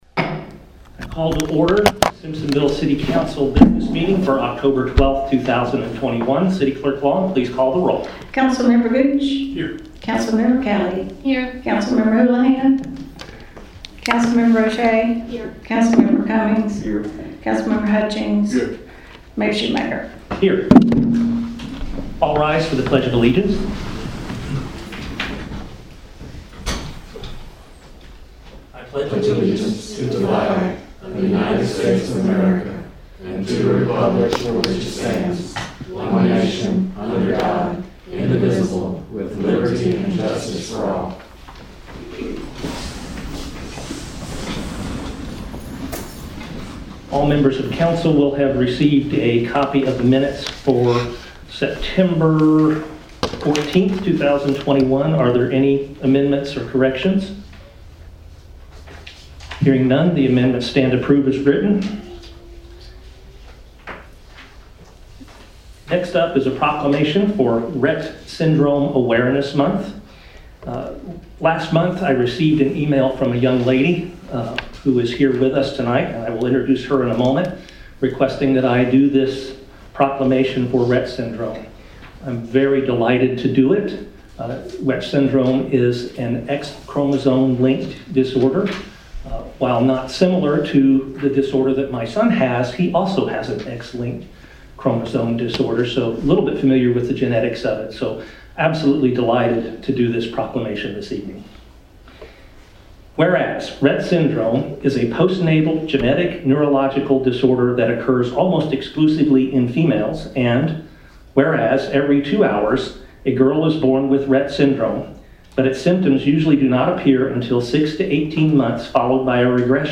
City Council Business Meeting
Simpsonville City Council will hold a regularly scheduled business meeting Oct. 10 at 6:30 p.m. in Council Chambers at City Hall.